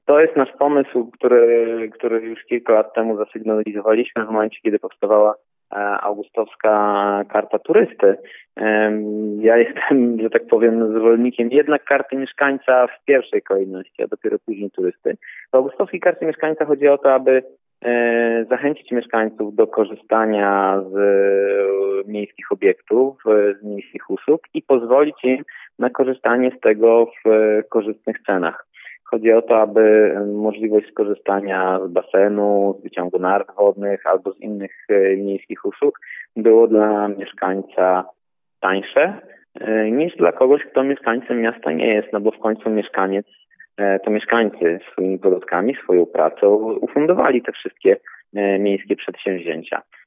Chodzi o zapewnienie augustowianom szeregu zniżek na usługi świadczone przez miejskie instytucje. Jak wyjaśnia Fiilip Chodkiewicz, zastępca burmistrza Augustowa, to właśnie mieszkańcy wypracowali wspólne dobra i zasługują na niższe stawki, niż osoby z zewnątrz.